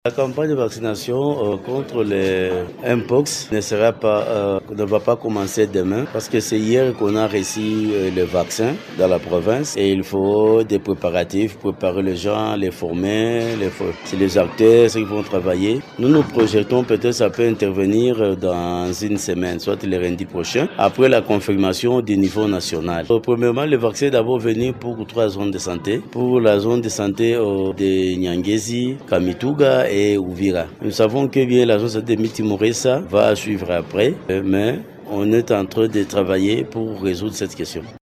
Ecoutez le ministre provincial de la santé, docteur Théophile Walulika qui explique